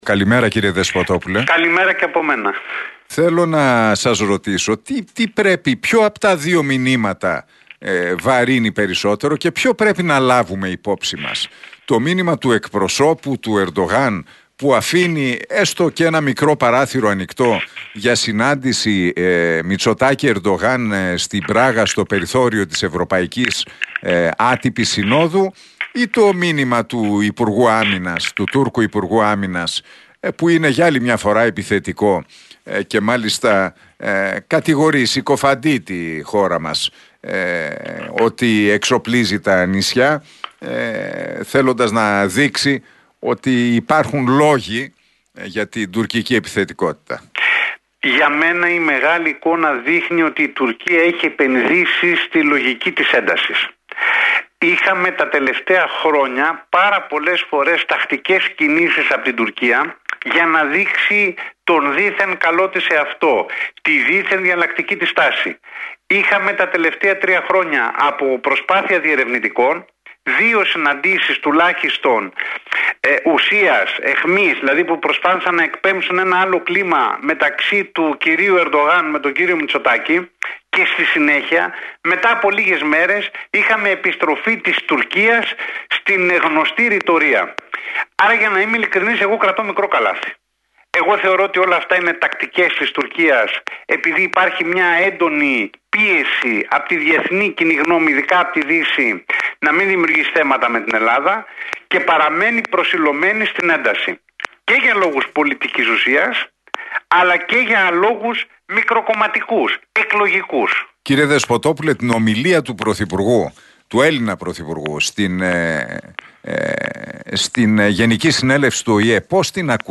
Για τις τουρκικές προκλήσεις μίλησε στον Realfm 97,8 και τον Νίκο Χατζηνικολάου ο διεθνολόγος